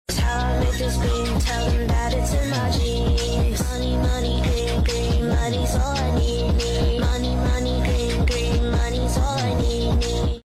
*Sound Kids* Dublin Bus | sound effects free download